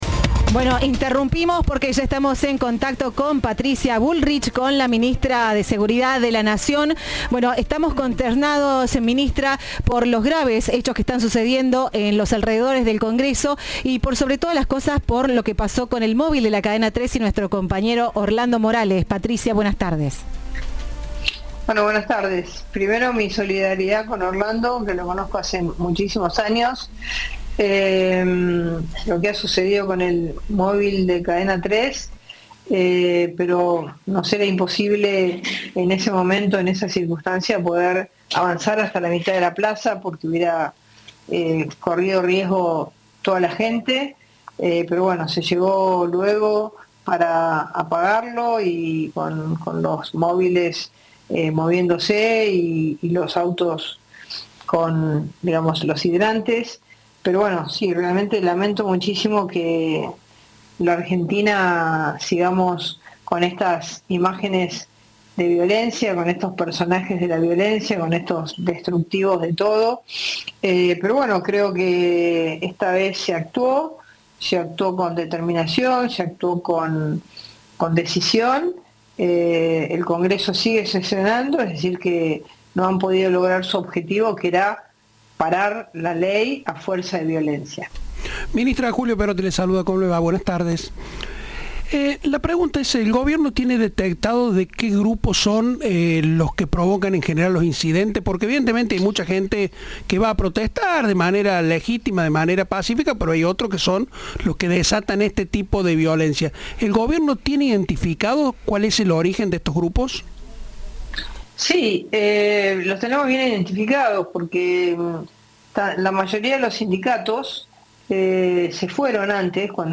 Entrevista de "Viva la Radio".